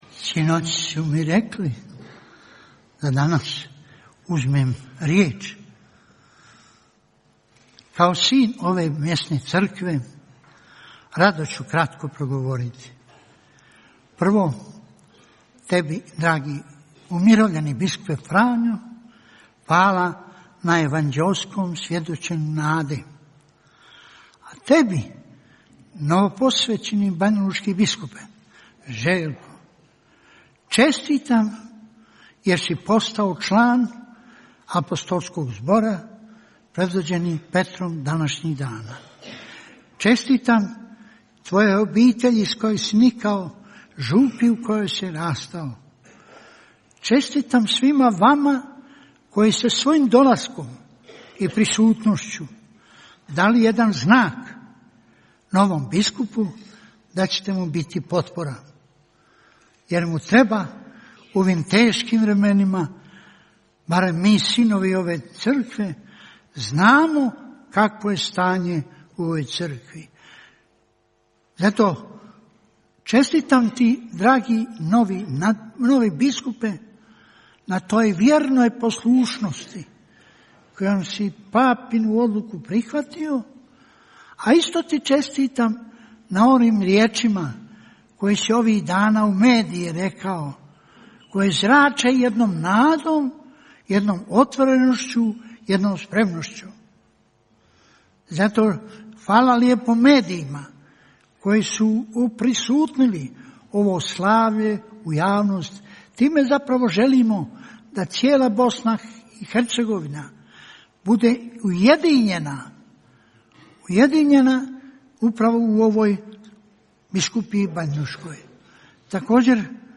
U subotu, 2. ožujka 2024. u katedrali sv. Bonaventure u Banjoj Luci, na kraju Euharistijskog slavlja, tijekom kojeg je za banjolučkog biskupa zaređen mons. Željko Majić, prigodnu čestitku novom pastiru Crkve banjolučke uputio je nadbiskup vrhbosanski u miru kardinal Vinko Puljić.